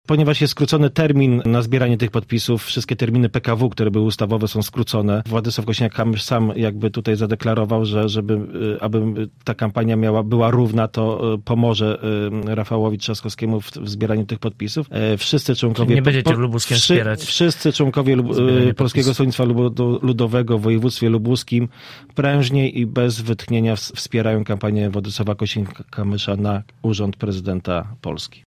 Takie wnioski płyną z dzisiejszej wypowiedzi działacza ludowców Łukasza Poryckiego na antenie Radia Zielona Góra.